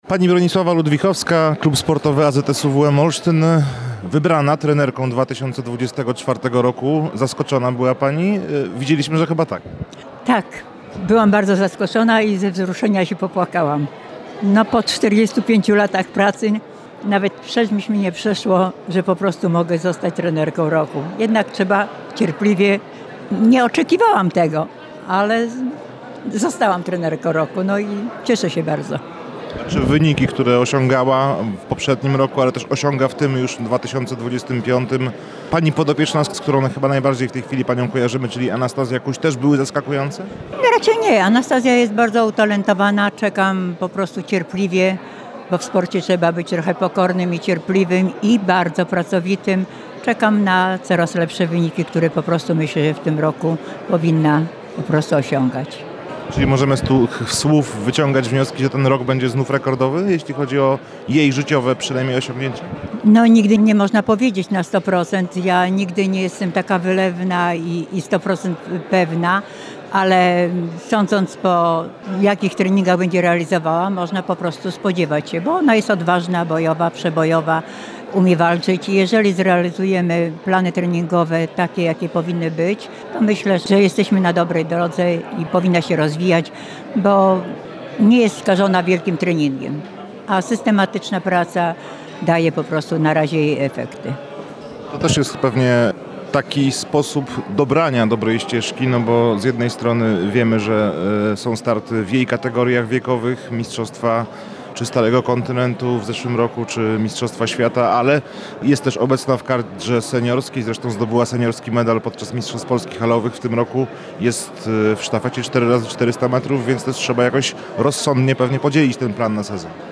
– mówiła nam podczas gali.